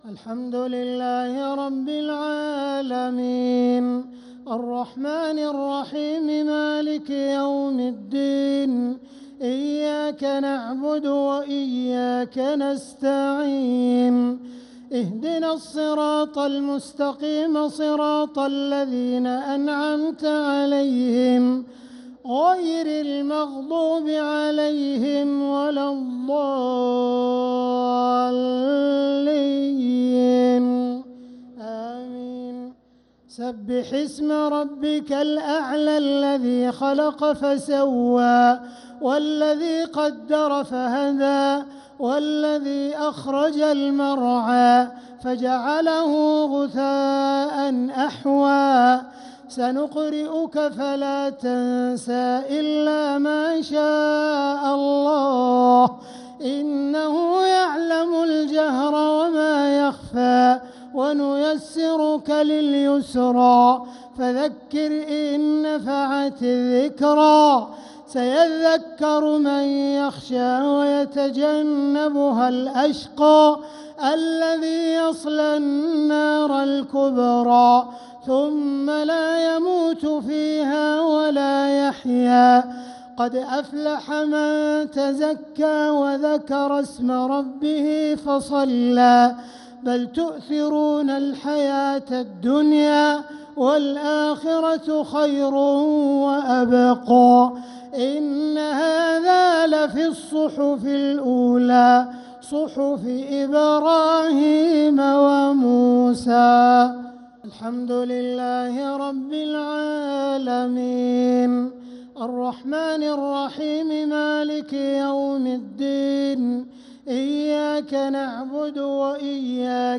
صلاة الشفع و الوتر ليلة 1 رمضان 1446هـ | Witr 1st night Ramadan 1446H > تراويح الحرم المكي عام 1446 🕋 > التراويح - تلاوات الحرمين